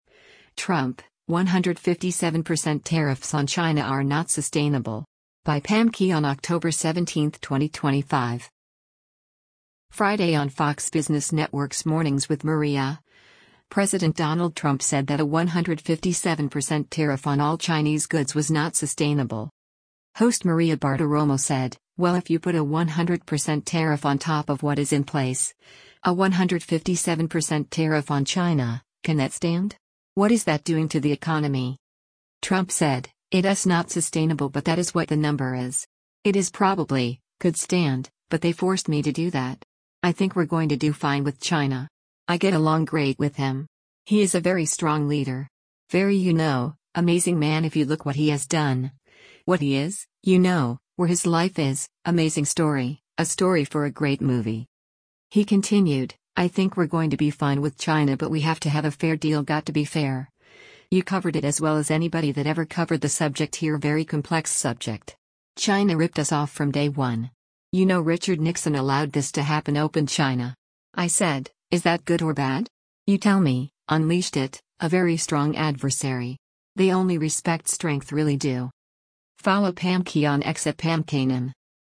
Friday on Fox Business Network’s “‘Mornings with Maria,” President Donald Trump said that a 157% tariff on all Chinese goods was “not sustainable.”